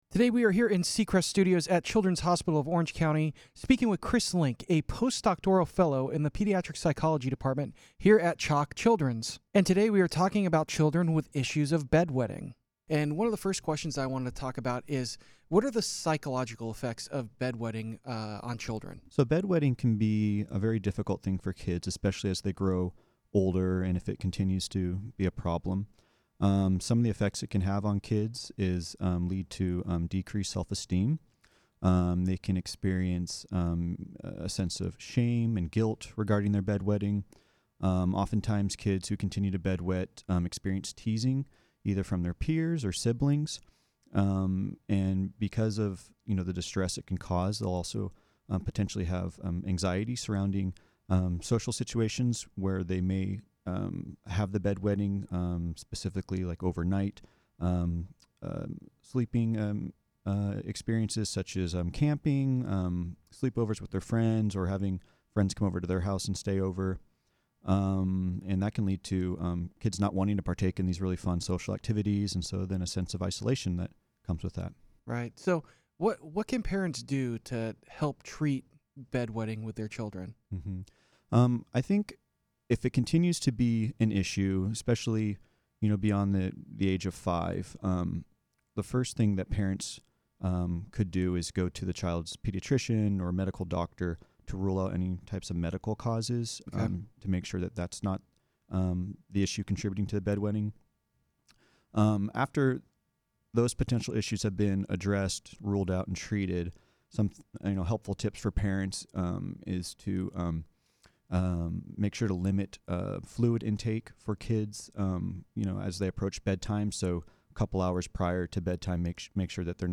to talk about what parents can do to help their children cope with bedwetting